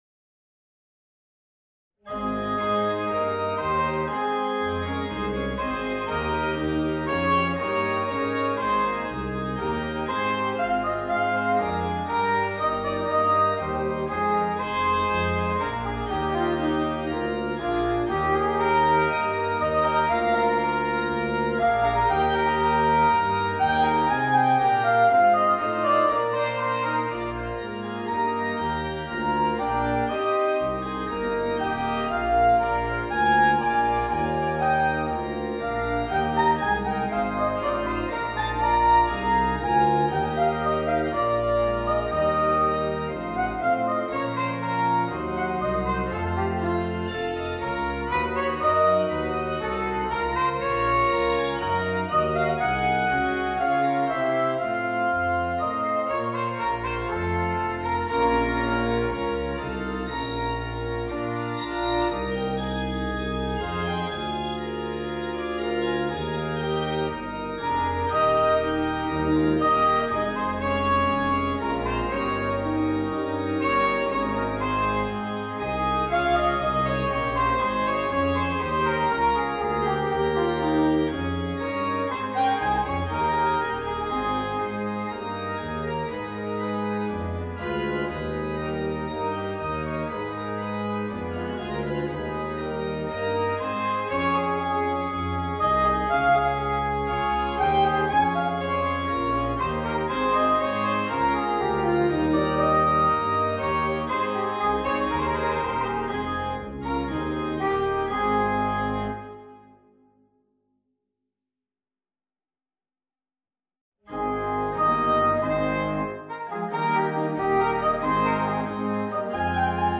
Voicing: Soprano Saxophone and Organ